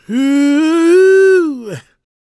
GOSPMALE027.wav